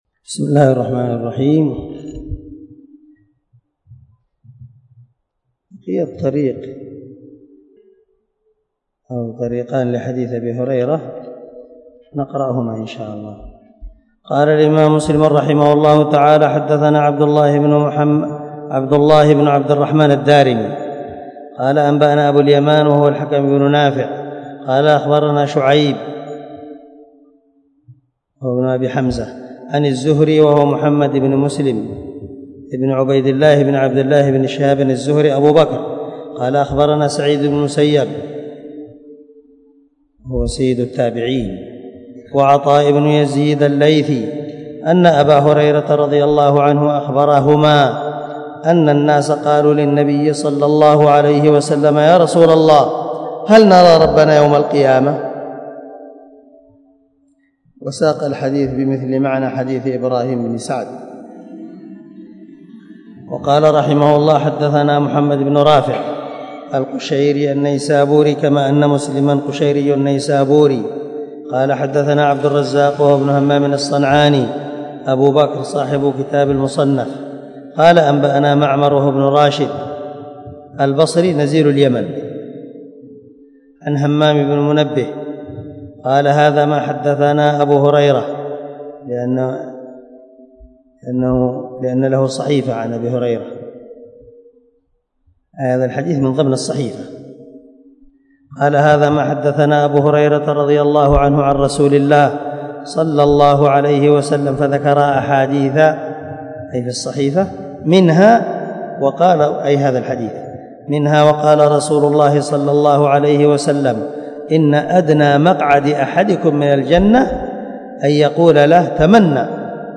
140الدرس 139 من شرح كتاب الإيمان تابع حديث رقم ( 182 ) من صحيح مسلم